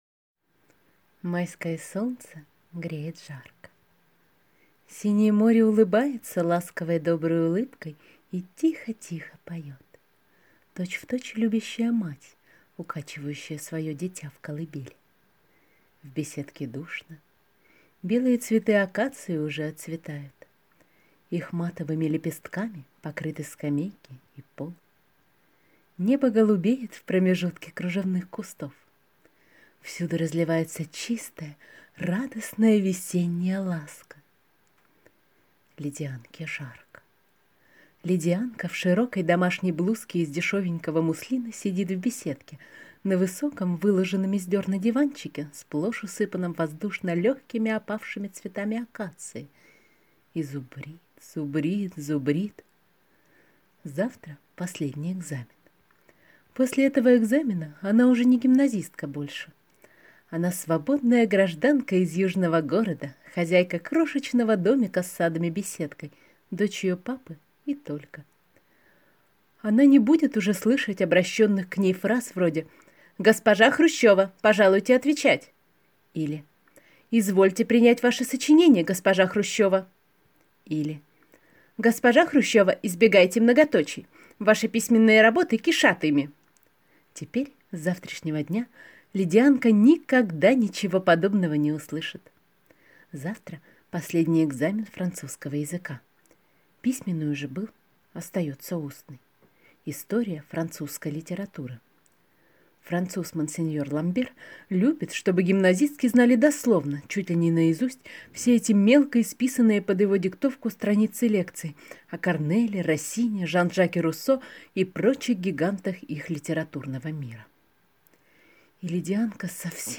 Аудиокнига Лидианка | Библиотека аудиокниг